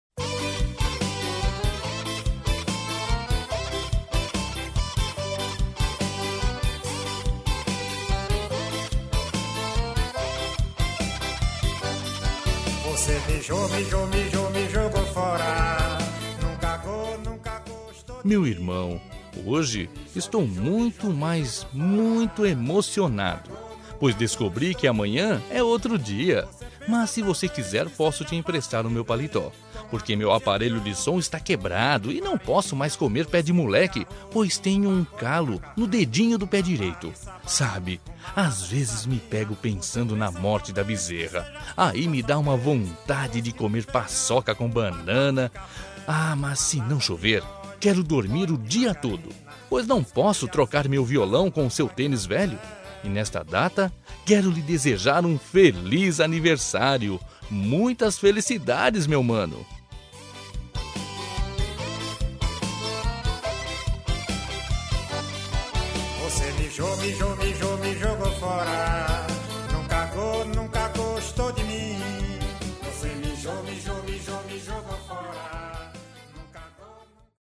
Aniversário de Humor – Voz Masculina – Cód: 200210